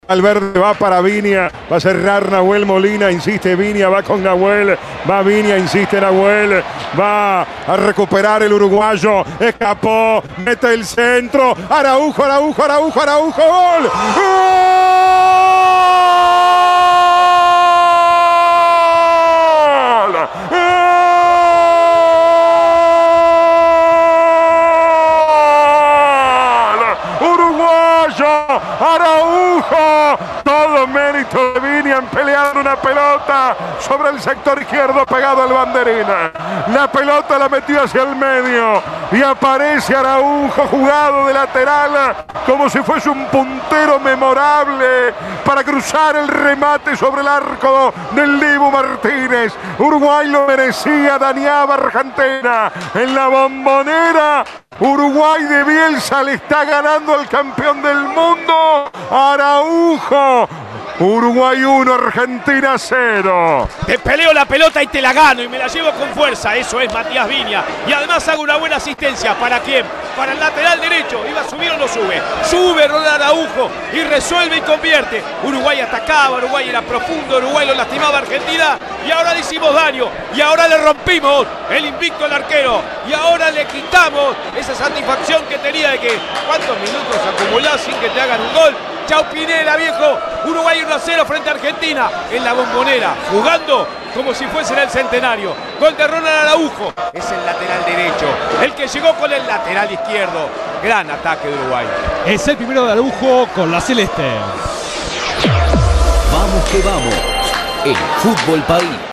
La histórica victoria celeste en la voz del equipo de Vamos que Vamos